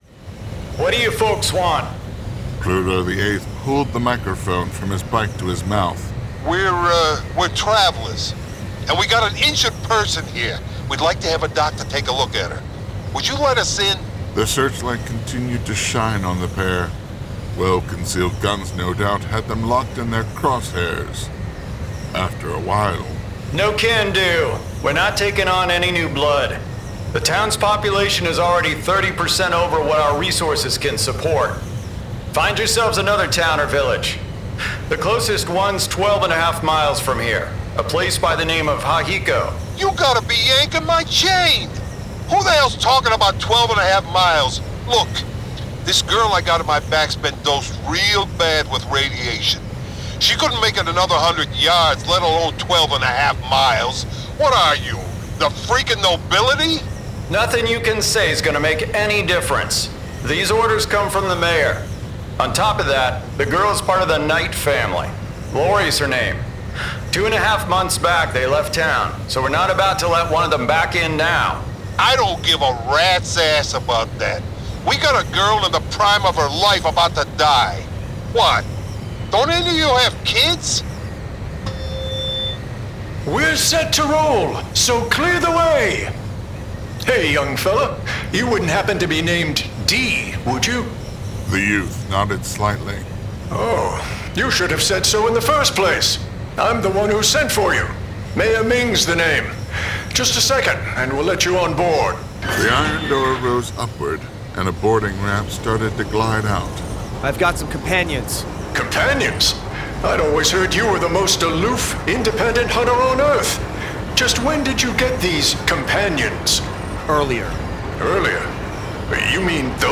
Full Cast. Cinematic Music. Sound Effects.
[Dramatized Adaptation]
Adapted from the novel and produced with a full cast of actors, immersive sound effects and cinematic music!